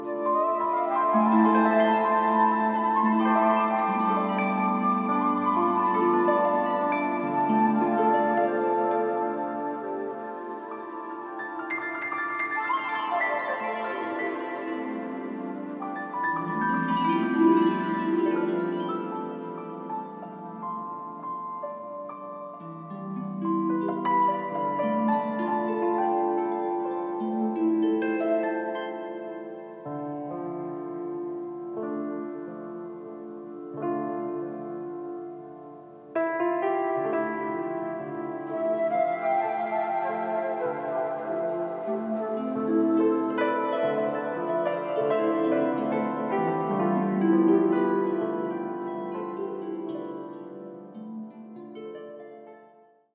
Music to Enhance Your own personal Home Spa Experience